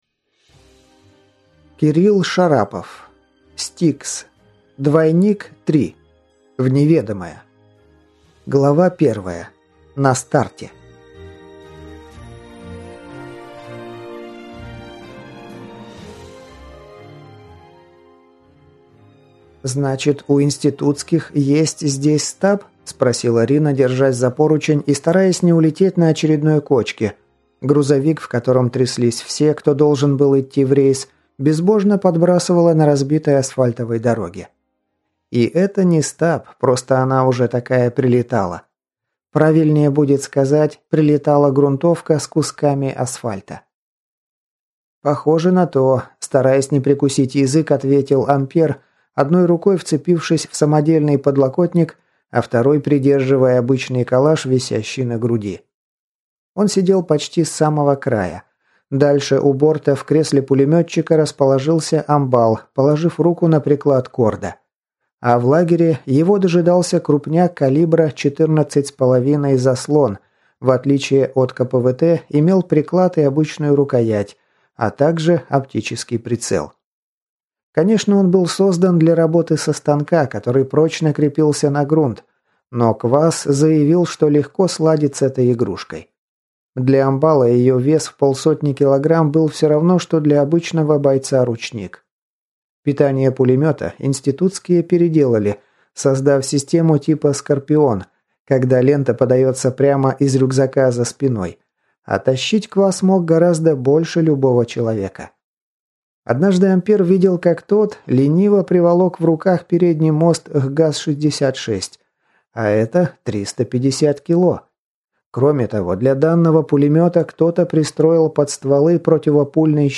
Аудиокнига S-T-I-K-S. В неведомое | Библиотека аудиокниг